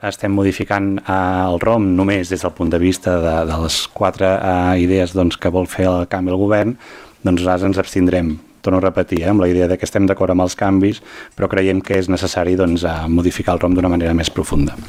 Recuperem les seves intervencions durant la sessió d’abril:
Cristian Ceballos, regidor d’Estimem Calella: